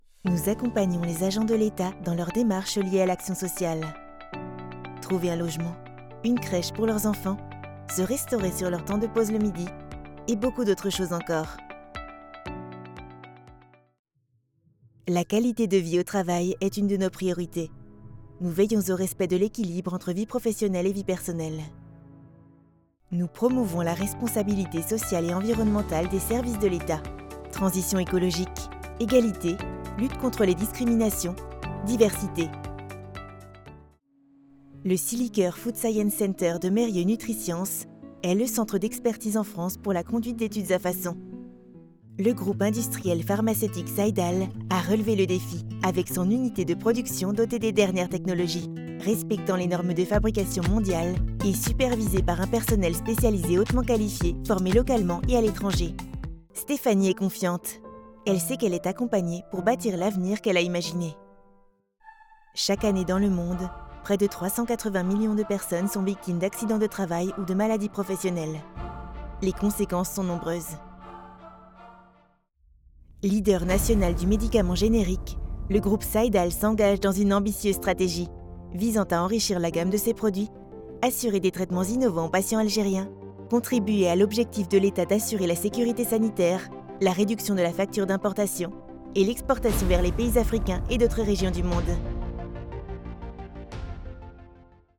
Commercieel, Jong, Speels, Warm, Zacht
Corporate
She has a medium voice.